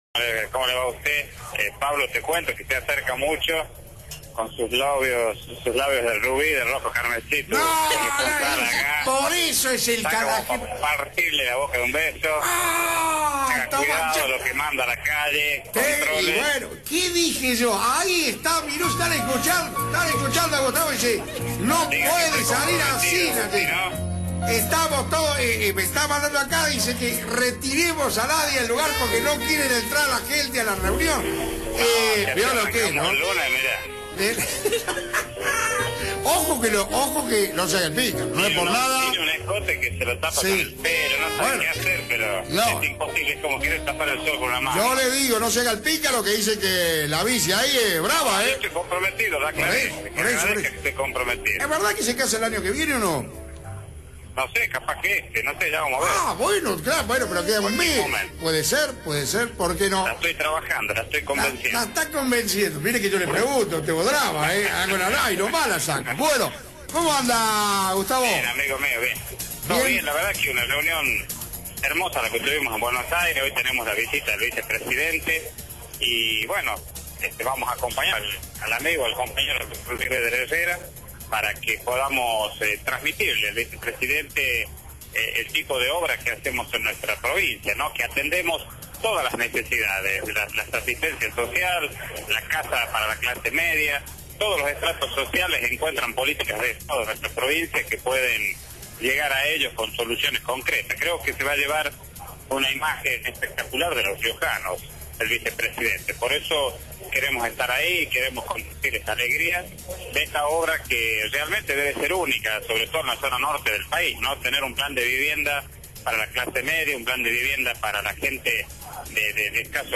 Gustavo Minuzzi, intendente de Arauco, por Radio Fénix
Minuzzi habló por Radio Fénix, en donde comentó que trata de convencer a la titular del Concejo Deliberante para contraer matrimonio antes que finalice el 2012, aunque luego dijo que podría ser el año entrante.